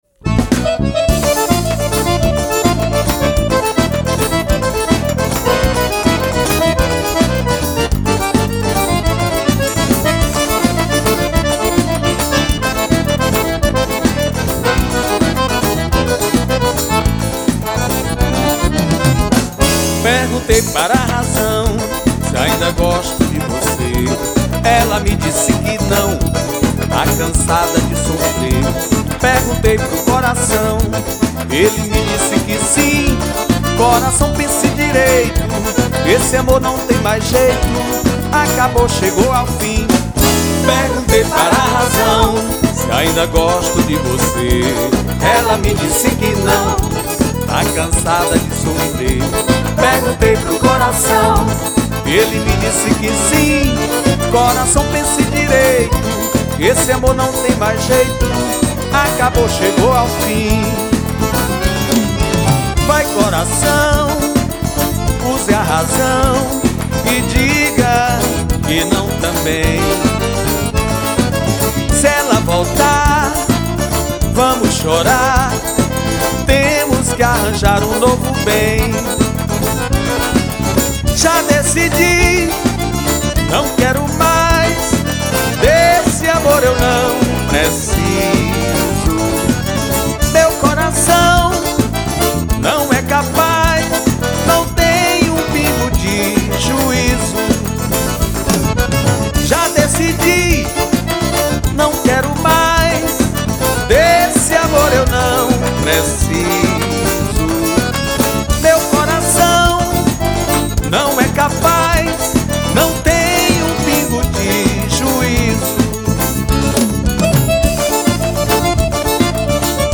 2529   03:57:00   Faixa: 8    Baião